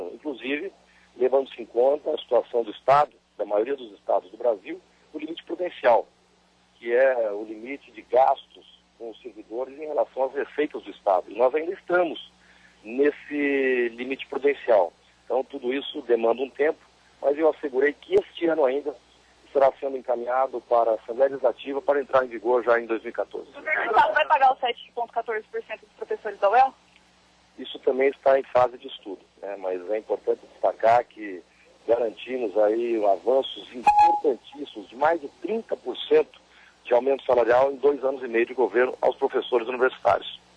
Às vésperas do prazo para pagar os 7,14% previsto em lei, Beto Richa disse em Londrina que o reajuste está “em fase de estudos”